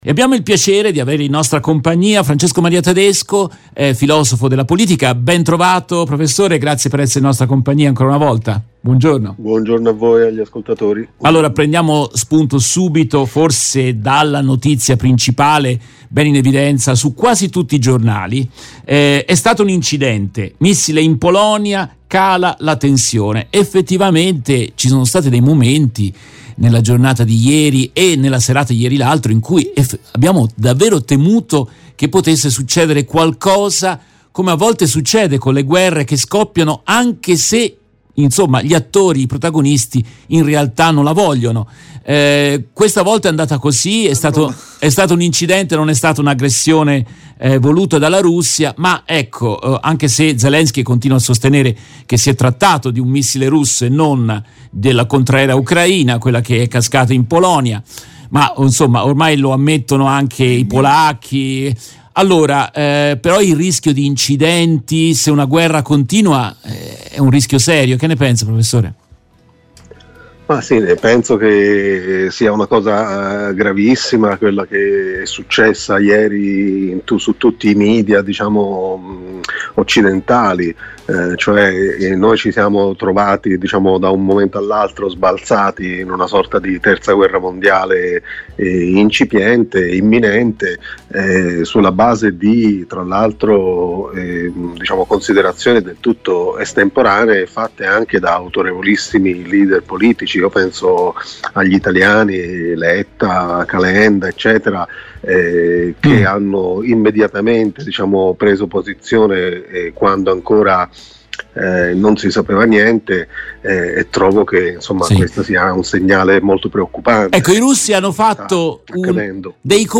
Nell’intervista estratta dalla diretta